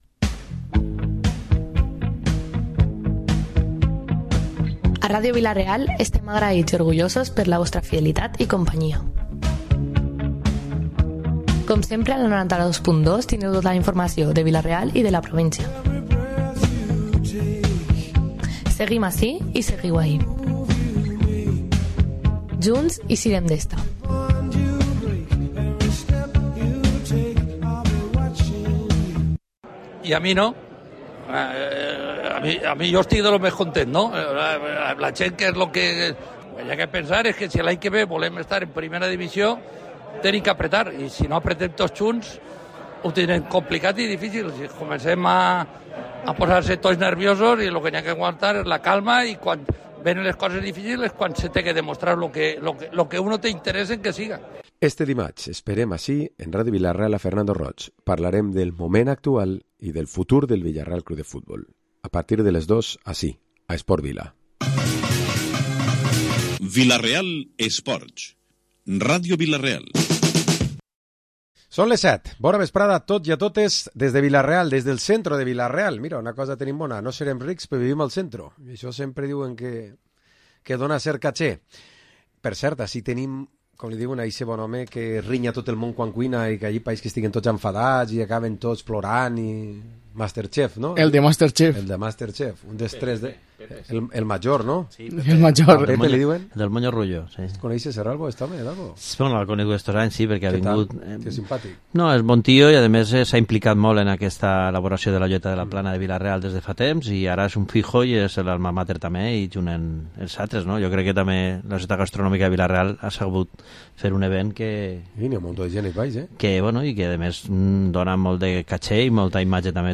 Programa esports tertúlia dilluns 16 d’octubre